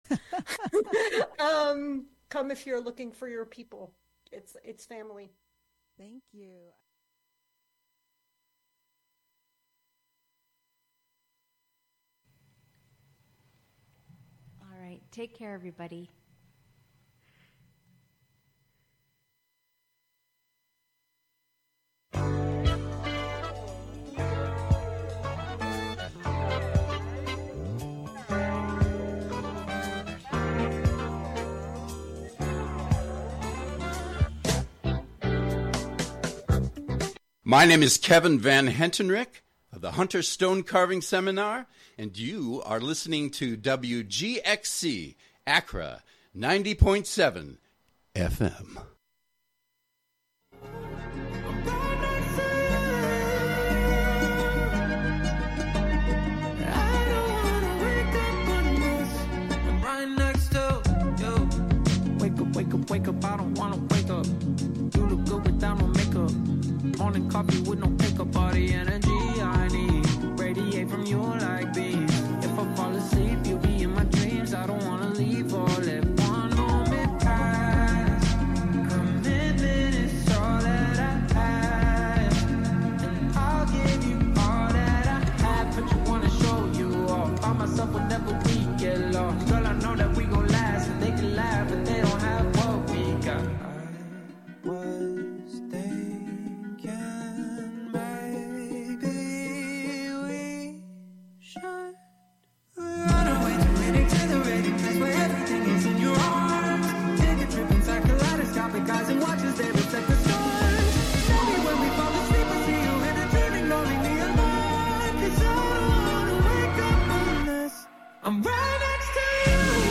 Through Censored, The Word Shop, and Our Town Our Truth, we dig into the topics that matter. Our container: Radiolab, an open, experimental, youth-led programming and recording space. Get yr weekly dose of music appreciation, wordsmithing, and community journalism filtered through the minds and voices of the Youth Clubhouses of Columbia-Greene, broadcasting out of the Catskill Clubhouse, live on Fridays and rebroadcast Sunday at 7 a.m.